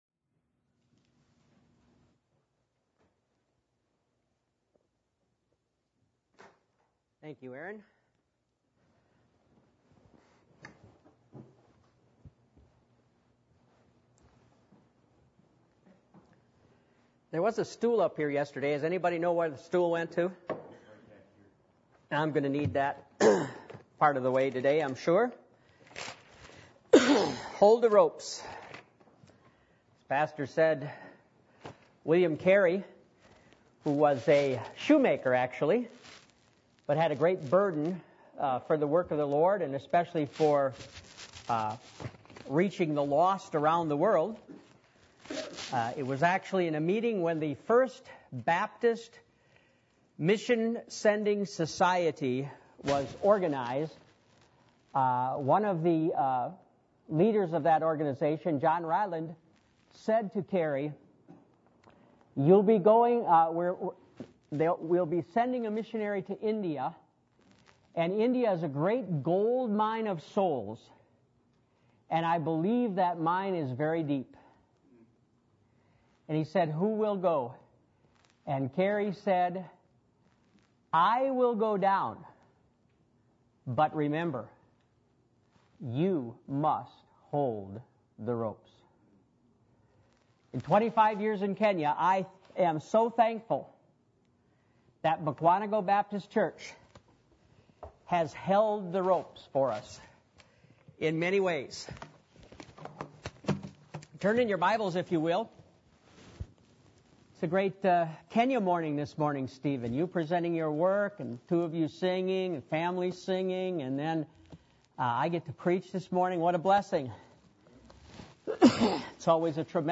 Passage: Ezekiel 22:23-30 Service Type: Sunday Morning